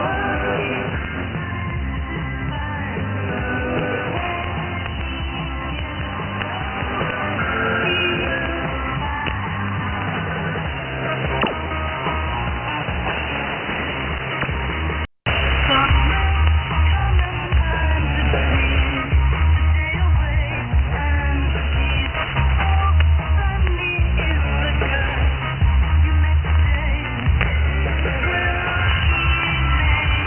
Für den Vergleich habe ich Audioaufnahmen der beiden Empfänger gemacht.
Sekunde 0-15 >> SDRplay RSPduo
Sekunde 15-30 >> Winradio G33DDC Excalibur Pro